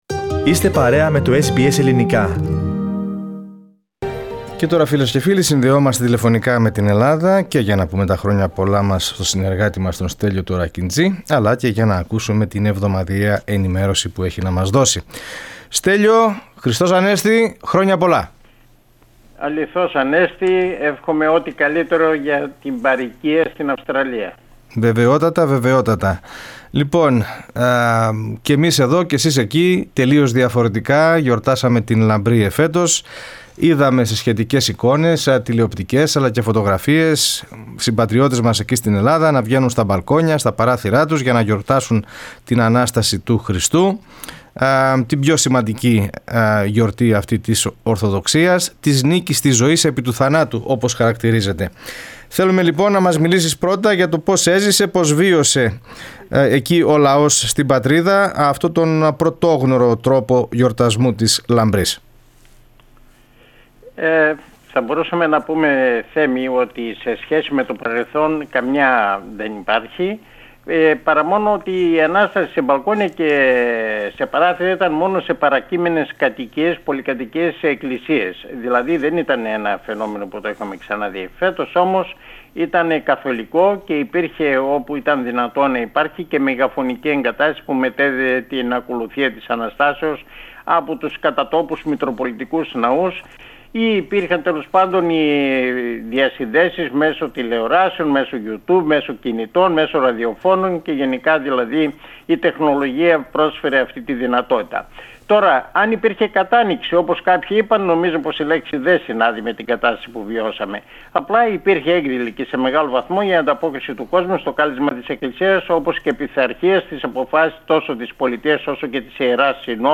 More in the weekly report from Greece, with our correspondent